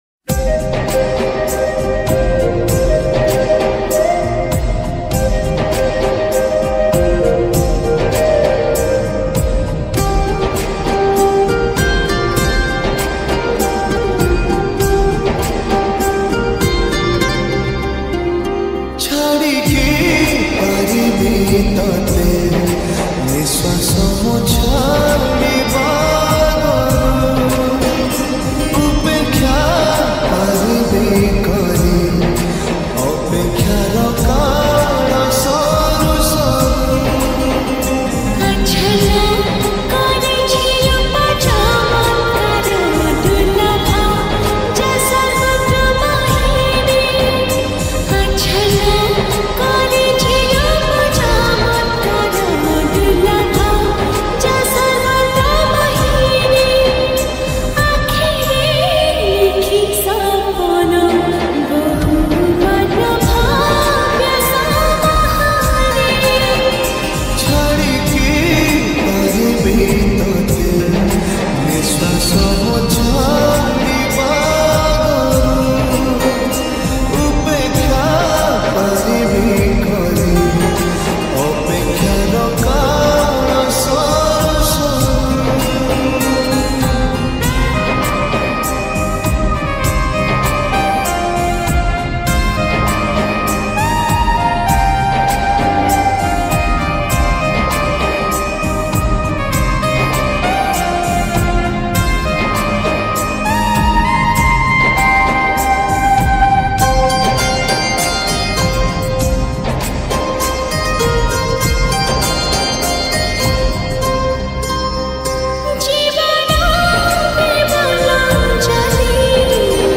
odia lofi song Duration